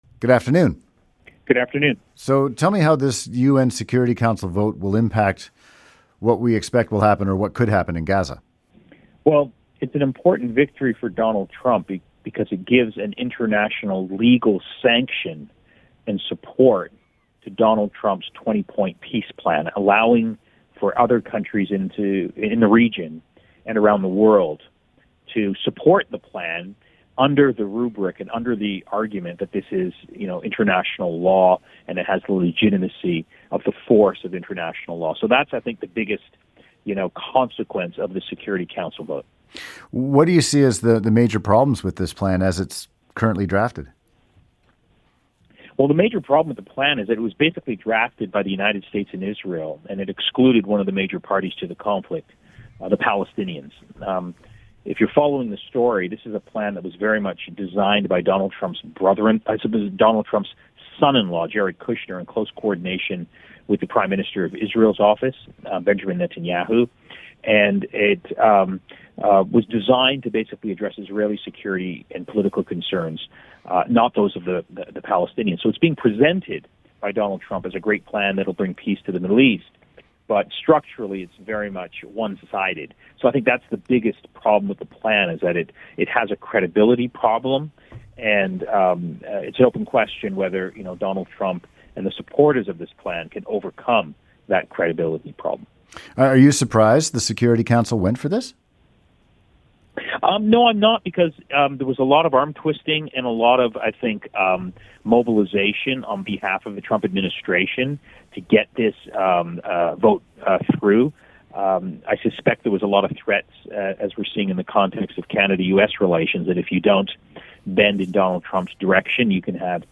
Media Interviews